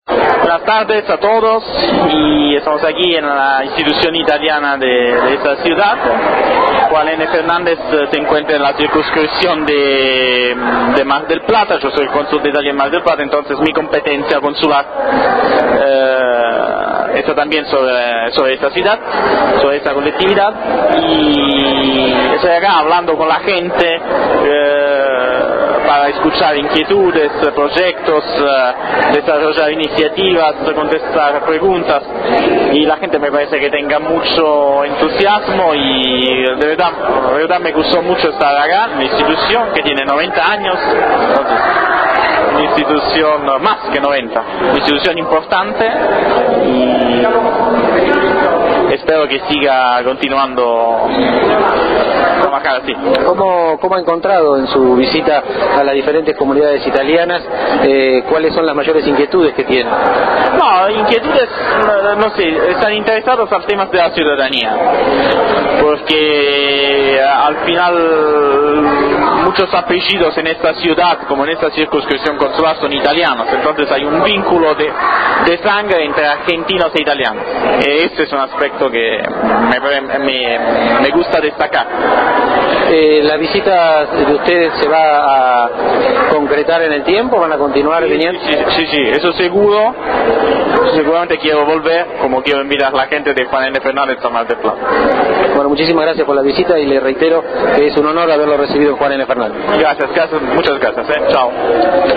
Escuchar audio del Cónsul, Dr. Fausto Panebianco